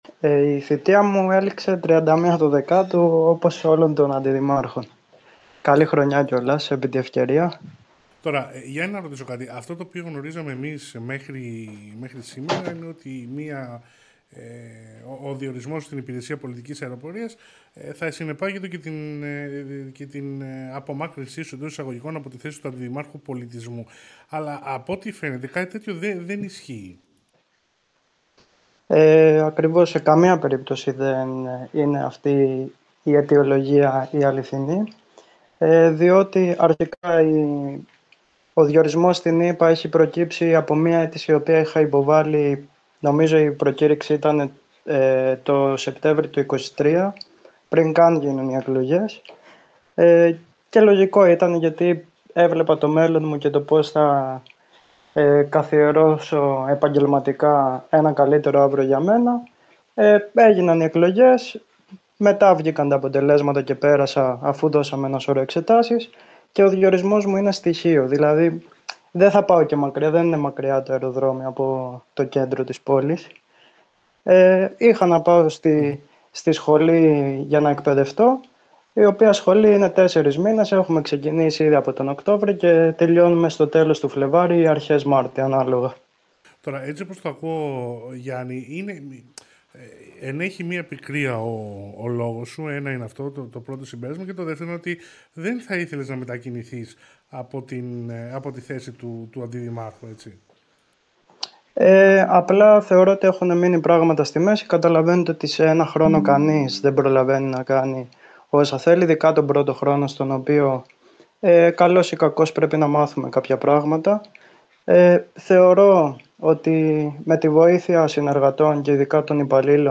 Τηλεφωνική συνέντευξη Γιάννη Αμέντα
Τηλεφωνική συνέντευξη Γιάννη Αμέντα.mp3